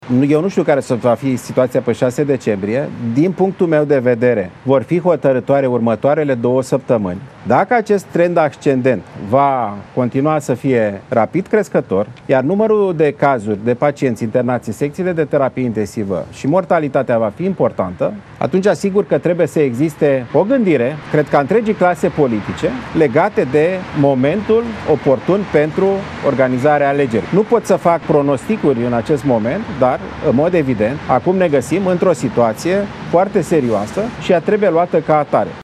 El a explicat, într-o declaraţie de presă, de ce s-a înscris în PSD: